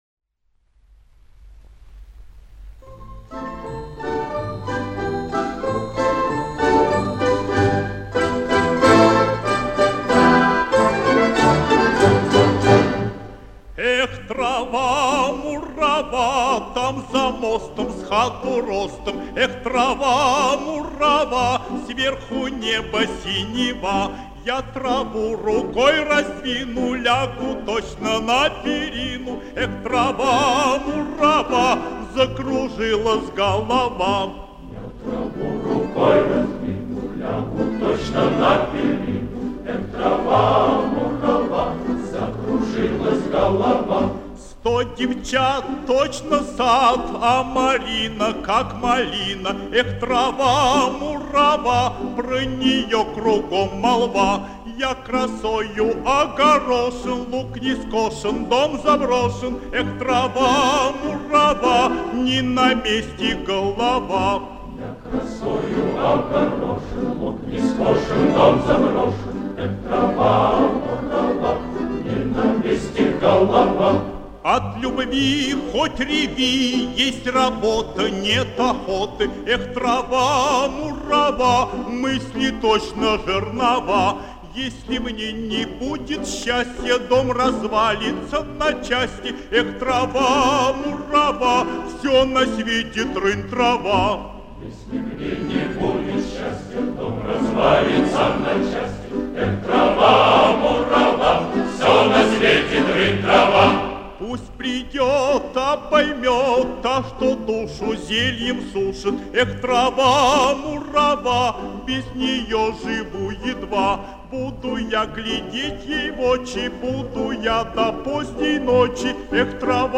Описание: Музыка: чешская народная песня, обр.